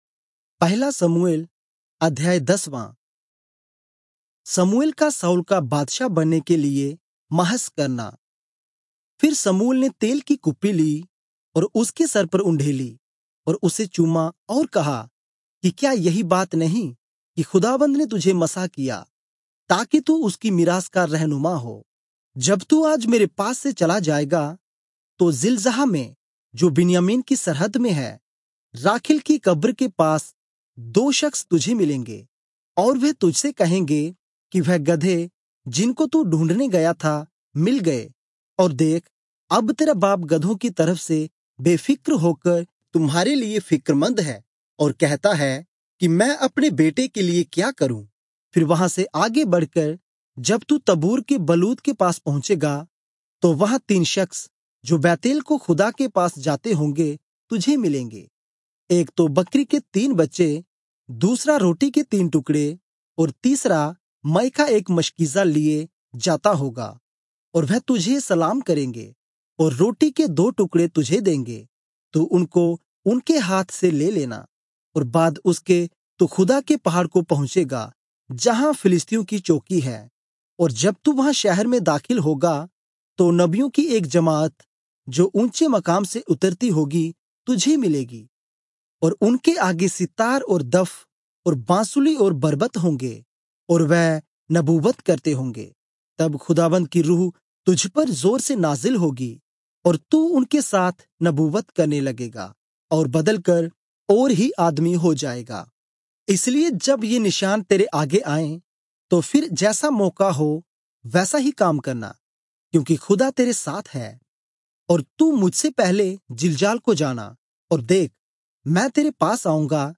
Urdu Audio Bible - 1-Samuel 10 in Irvur bible version